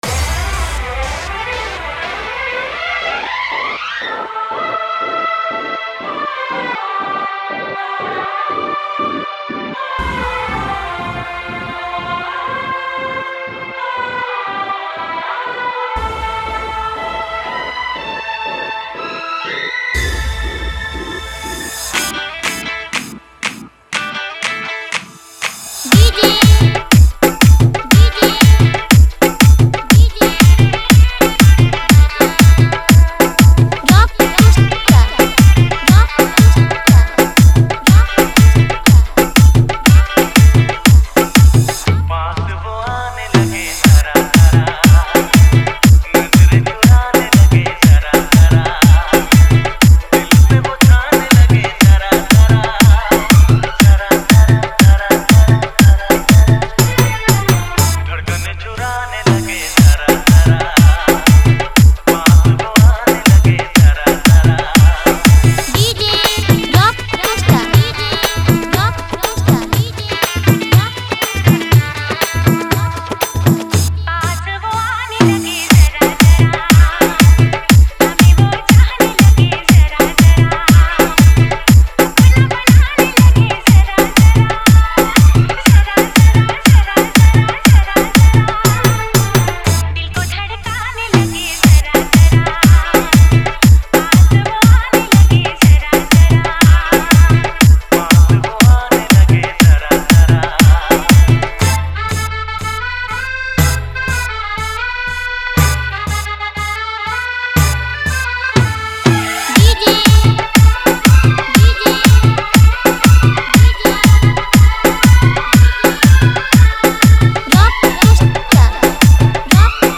Category:  Love Dj Remix